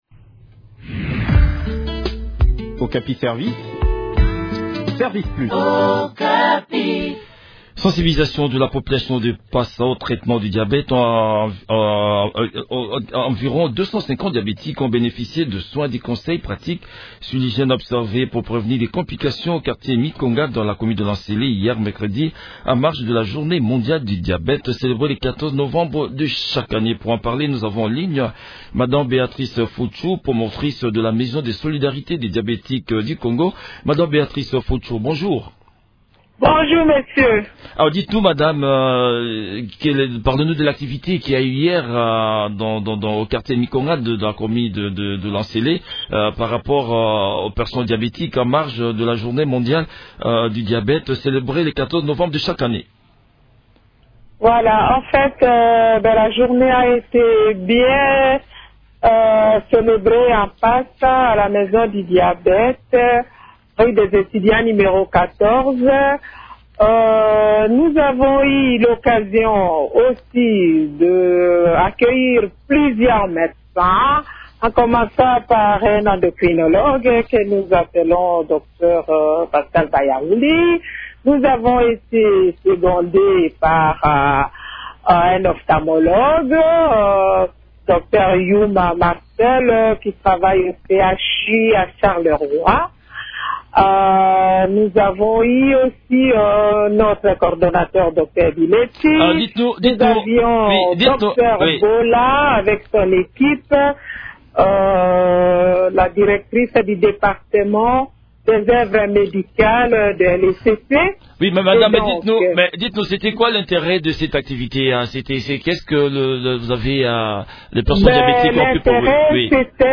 Le point sur le déroulement de cette activité dans cet entretien